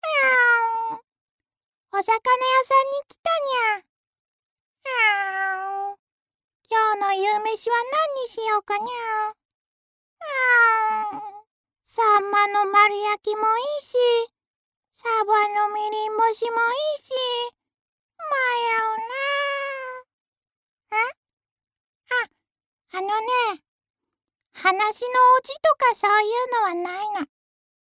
アトリエピーチのサンプルボイス一覧および紹介